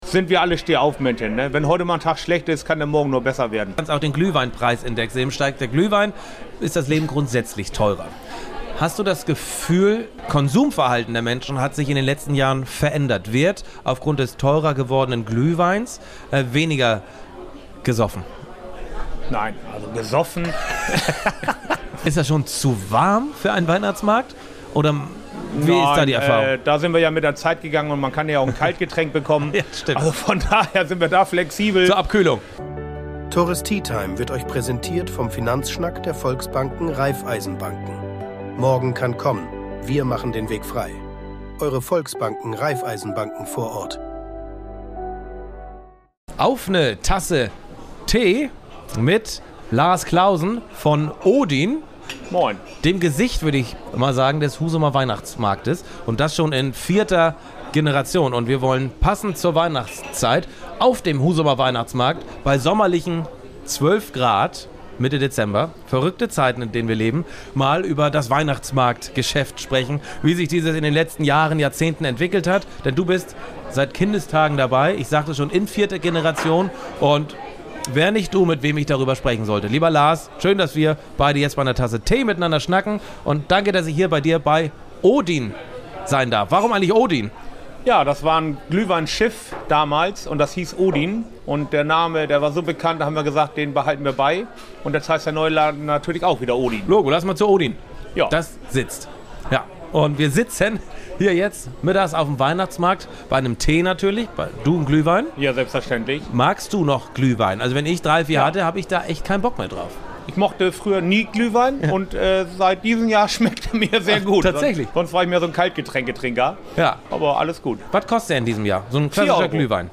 Und wie sehr beeinflusst das Wetter das Geschäft? Eine Folge mitten aus dem Herzen des Weihnachtsmarktes, direkt am Stand, mit ehrlichen Einblicken in ein traditionsreiches Gewerbe.